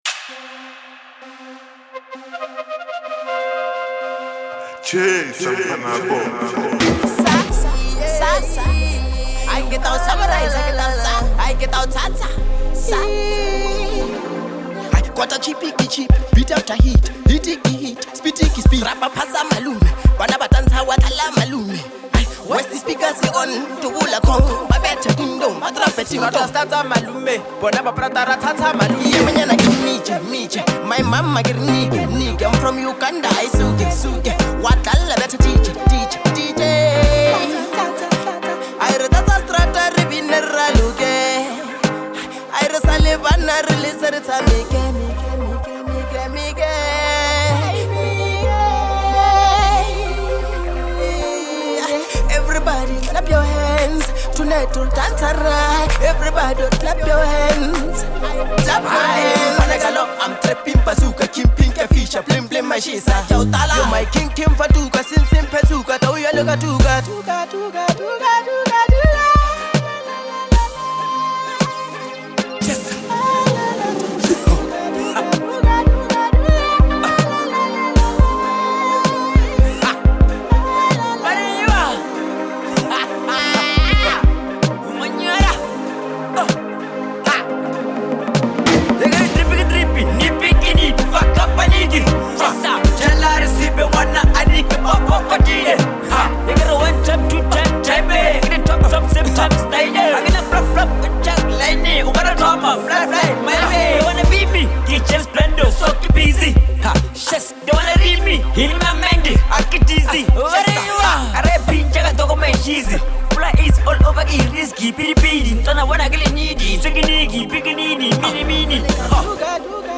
is a deeply emotional and reflective track